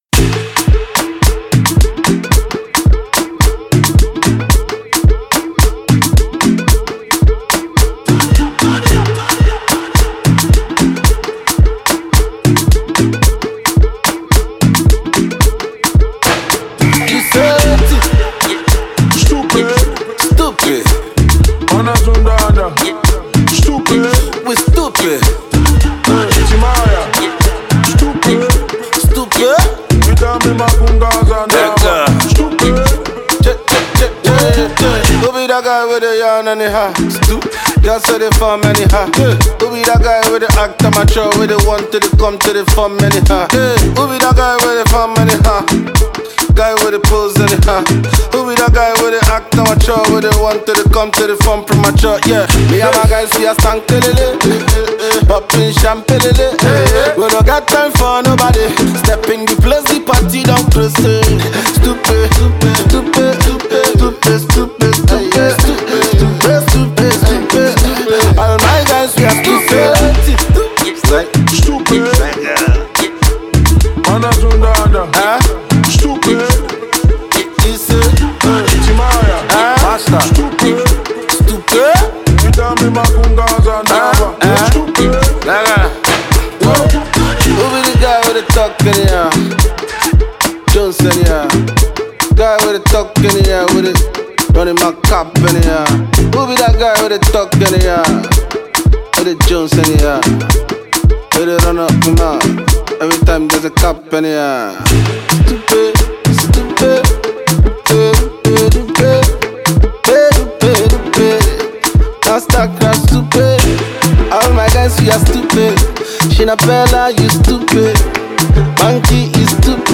a new party jam